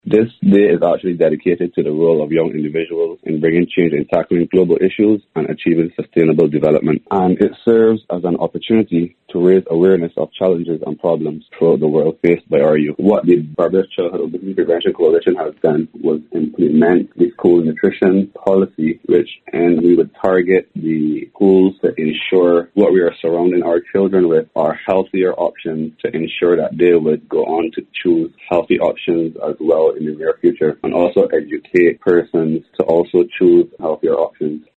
Voice of: Member of the Barbados Childhood Obesity Prevention Coalition